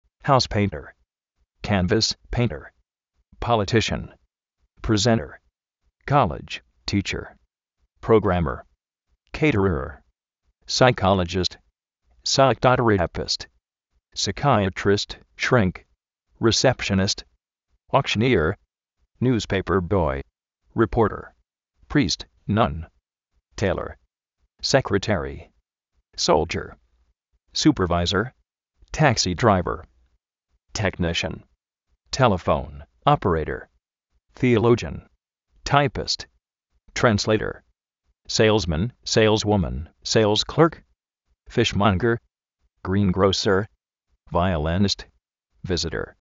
jáus péinter
politíshan
presénter
prográmer